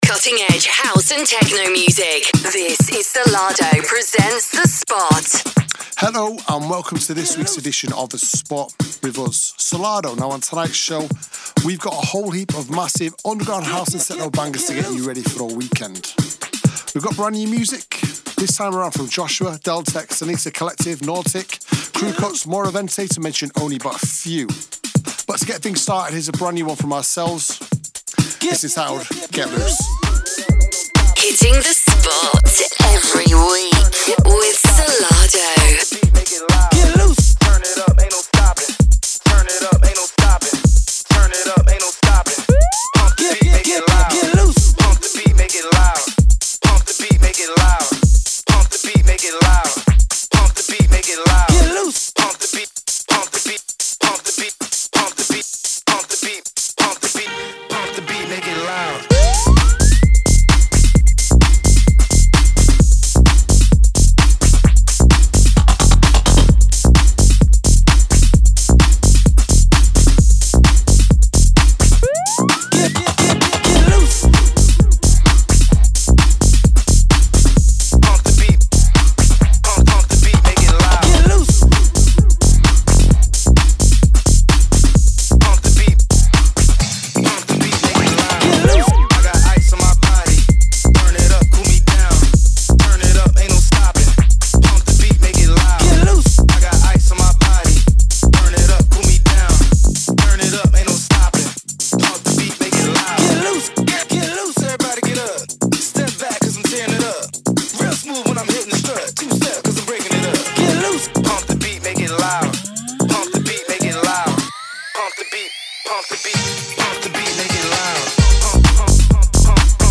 music DJ Mix in MP3 format
Genre: Tech House